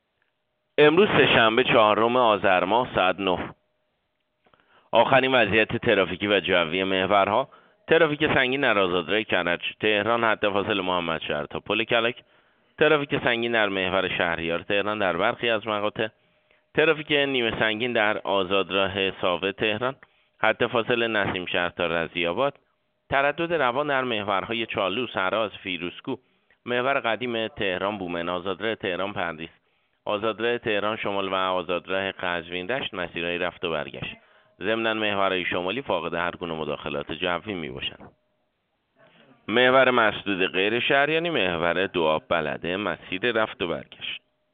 گزارش رادیو اینترنتی از آخرین وضعیت ترافیکی جاده‌ها ساعت ۹ چهارم آذر